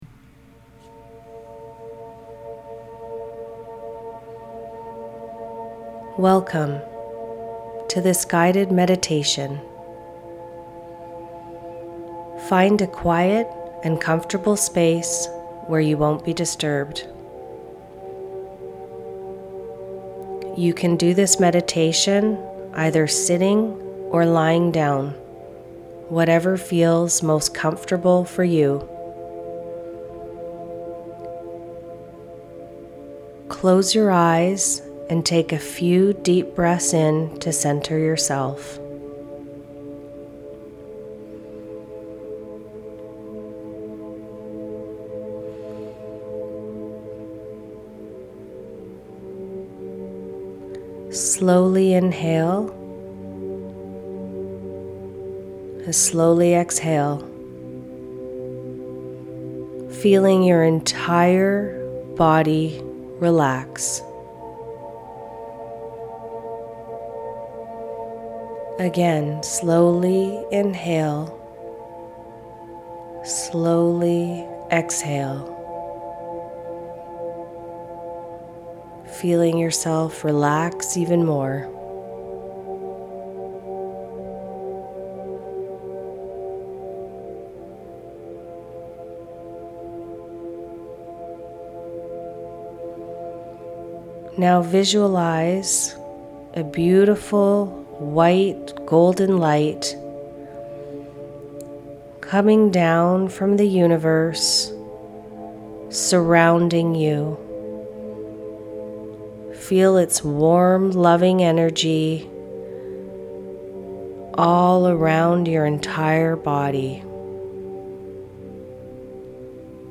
Ease tension and quiet your mind with this gentle meditation, designed to help you release stress, relax deeply, and feel centred.